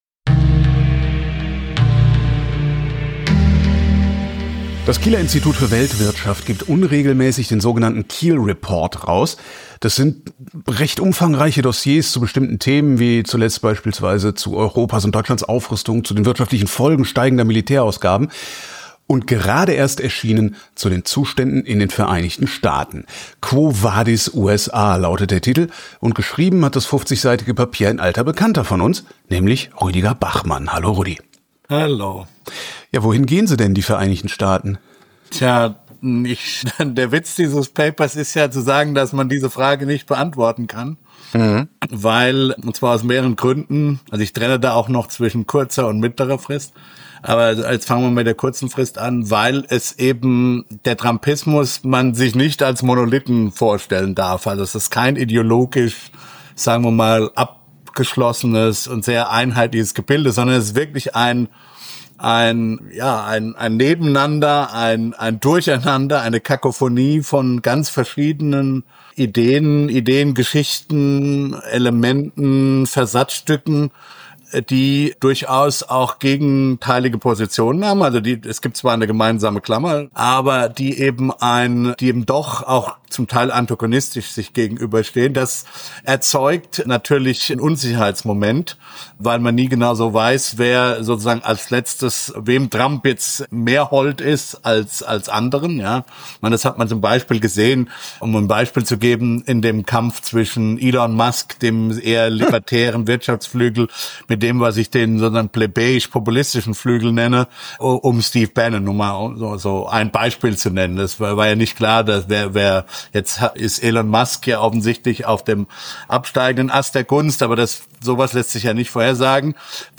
(Ich habe aus Zeitmangel sehr räudig geschnitten. Bitte entschuldigt.)